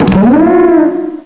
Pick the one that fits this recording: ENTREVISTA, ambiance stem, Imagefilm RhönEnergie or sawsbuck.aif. sawsbuck.aif